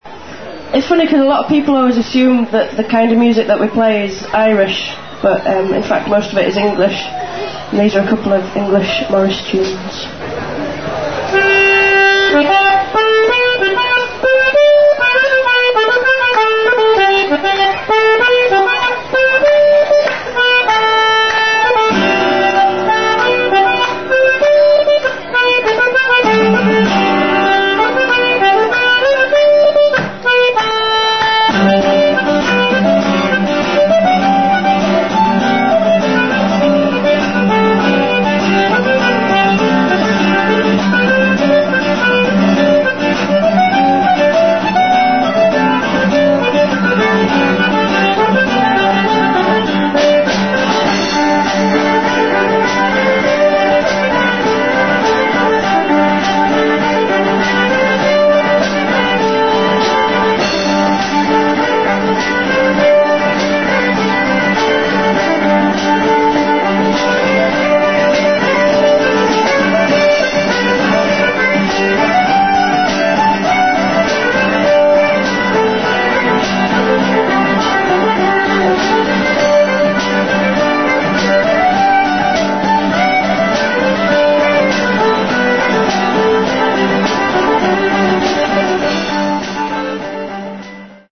English morris tune.